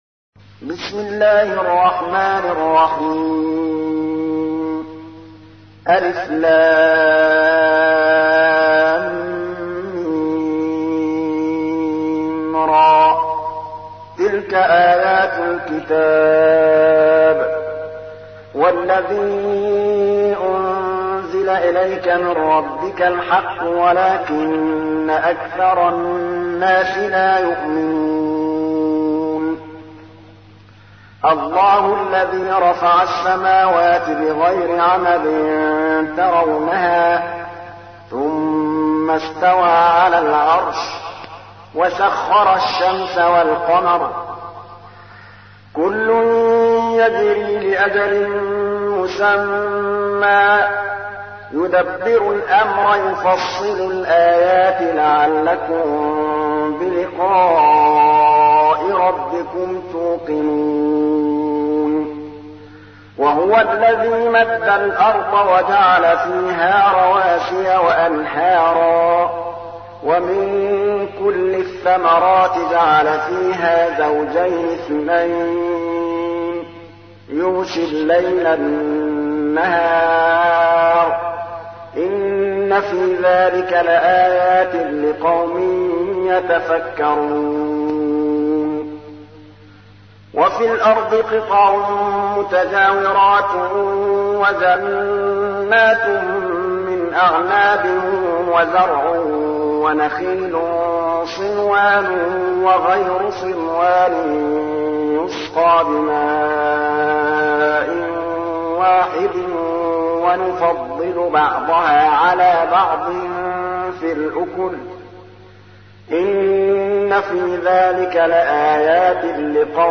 تحميل : 13. سورة الرعد / القارئ محمود الطبلاوي / القرآن الكريم / موقع يا حسين